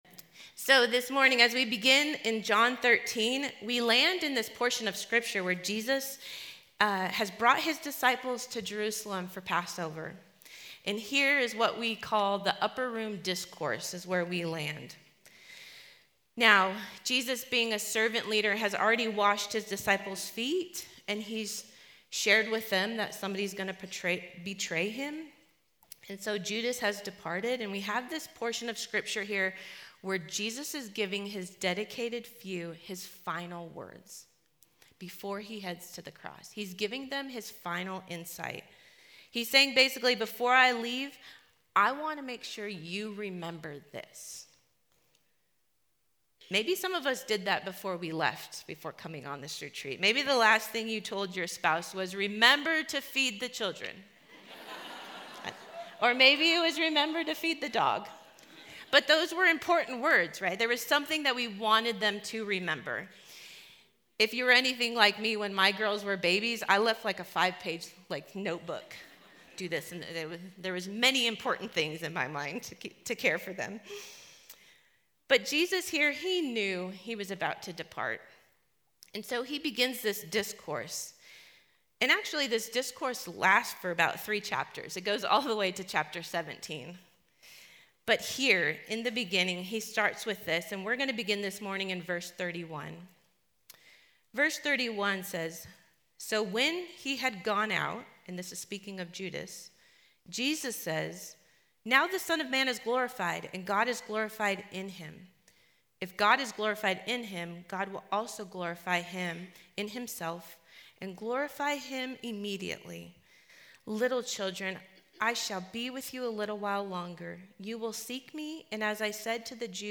Home » Sermons » Commanded to Love
Conference: Women's Gathering